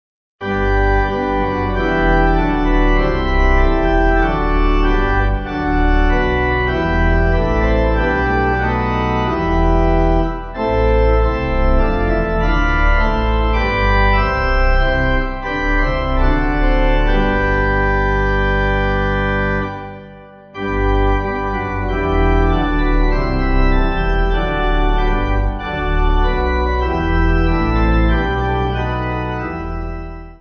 Organ
(CM)   6/G